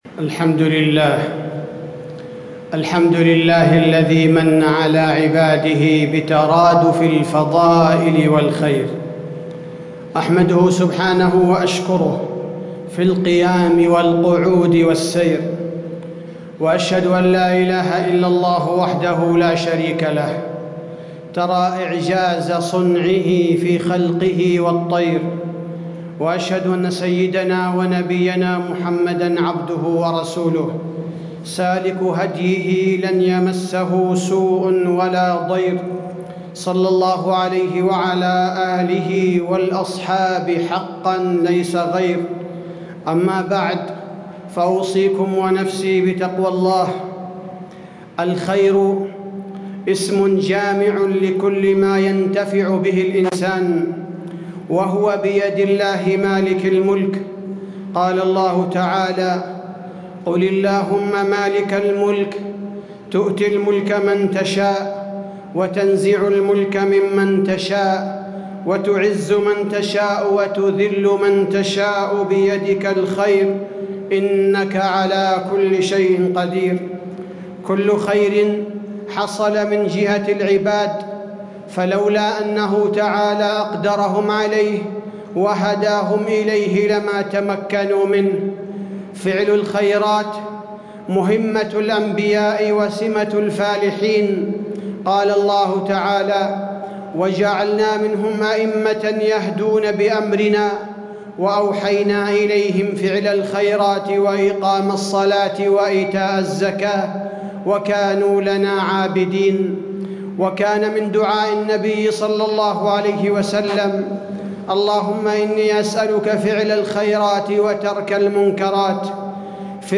تاريخ النشر ٢٥ شعبان ١٤٣٦ هـ المكان: المسجد النبوي الشيخ: فضيلة الشيخ عبدالباري الثبيتي فضيلة الشيخ عبدالباري الثبيتي الخير ومظاهره وأماراته